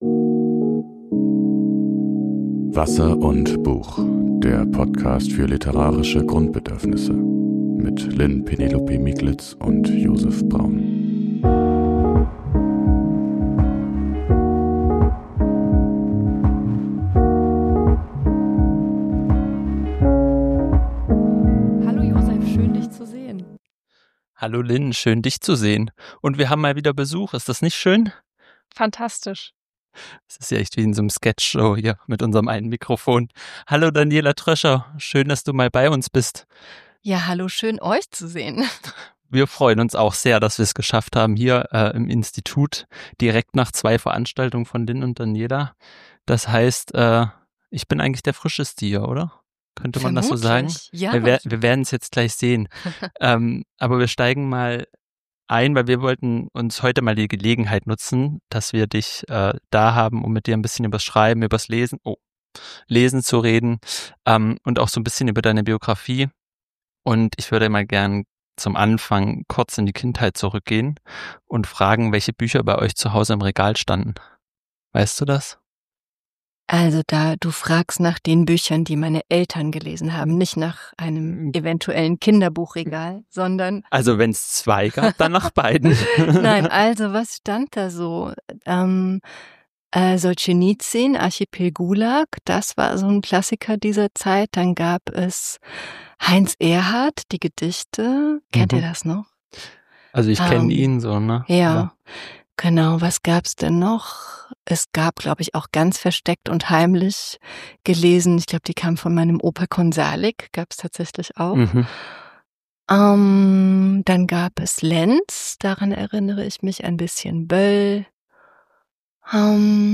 Denn wir sind mal wieder zu dritt in unserem Studio. Zu Gast ist die Schriftstellerin Daniela Dröscher.